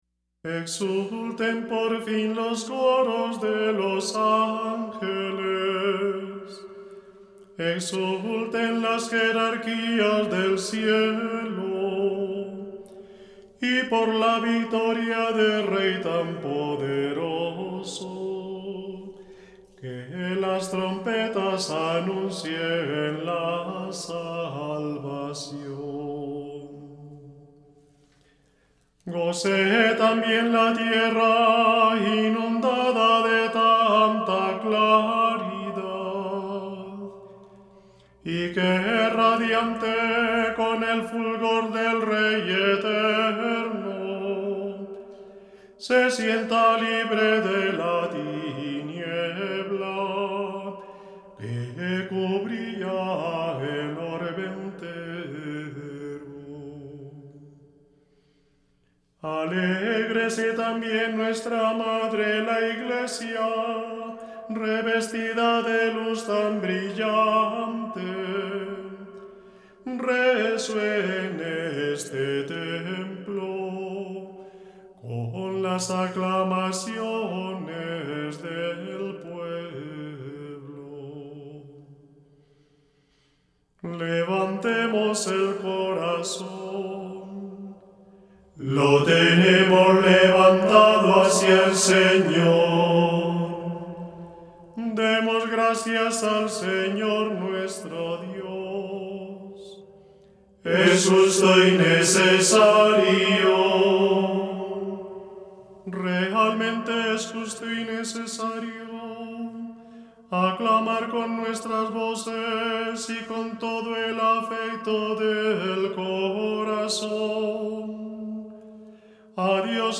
Clásica